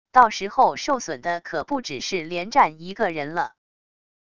到时候受损的可不只是连战一个人了wav音频生成系统WAV Audio Player